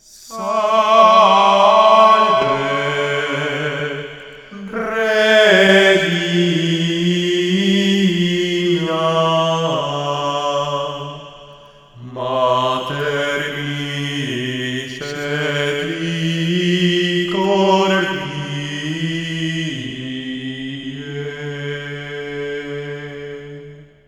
Canto Gregoriano